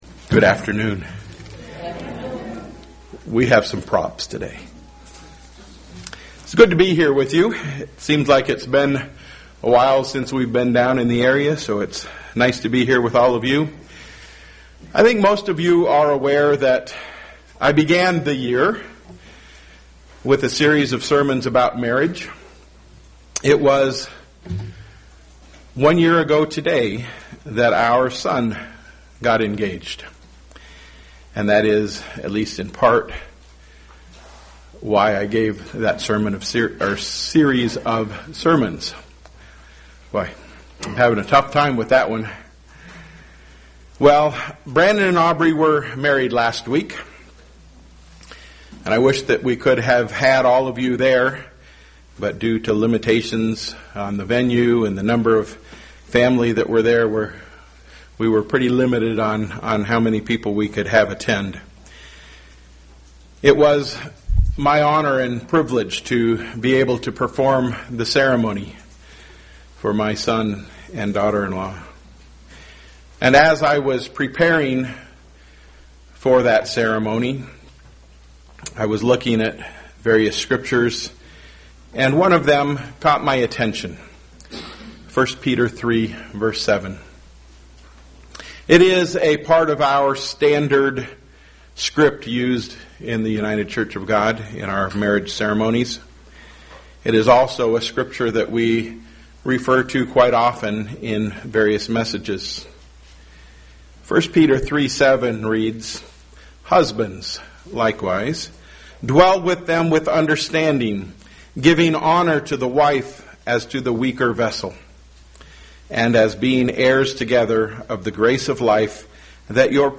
Given in Flint, MI
UCG Sermon Studying the bible?